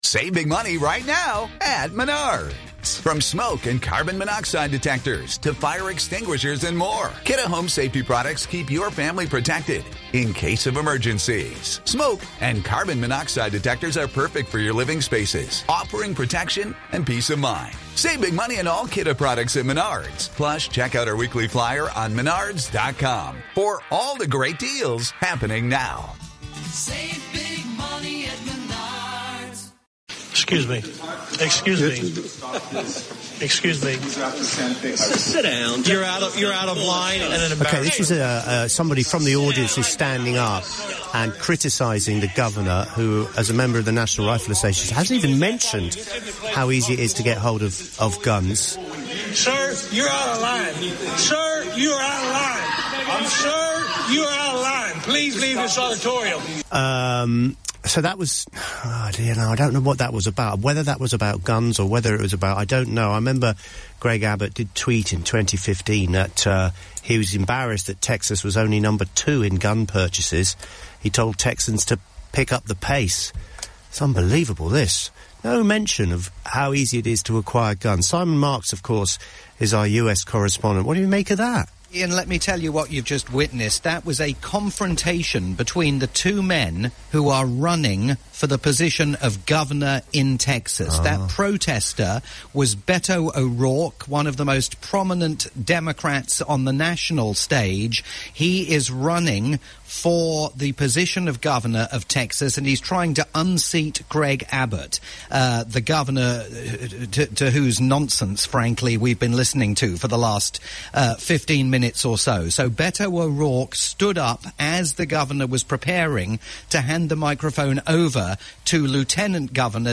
live coverage for LBC News